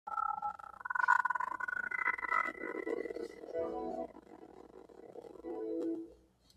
Windows 7 Startup Sound Glitched Sound Effect Download: Instant Soundboard Button